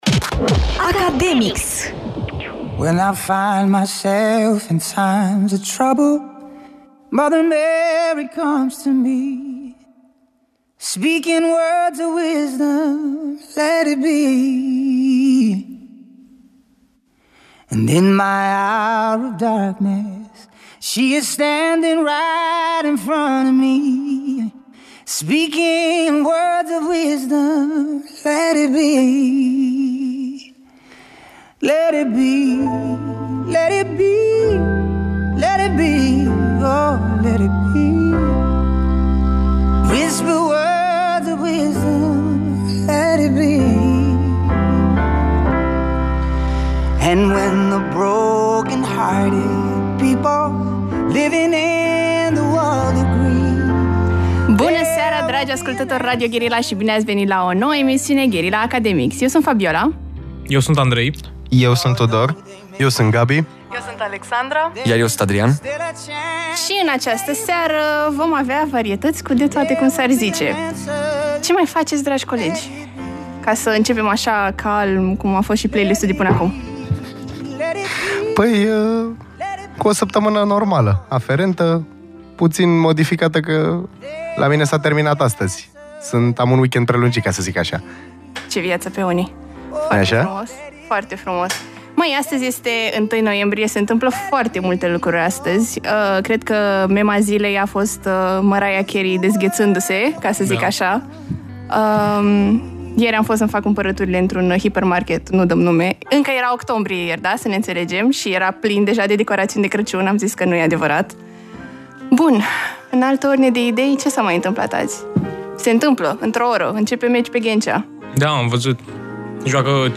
Academics este emisiunea de la Radio Guerrilla ce îi are drept gazde pe elevii din primele sezoane Uman Real, care acum au crescut și au devenit studenți.